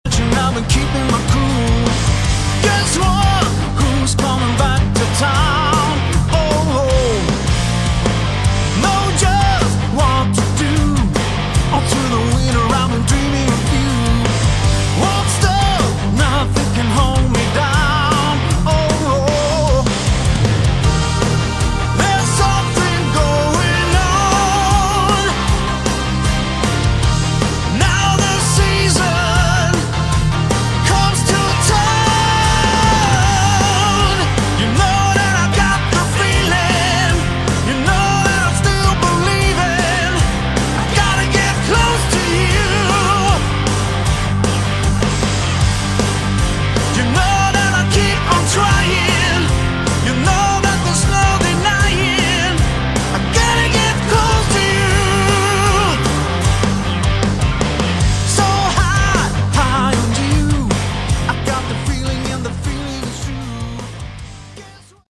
Category: Melodic Rock
lead vocals
guitar, bass, keyboards, backing vocals
drums, additional Keyboards, backing vocals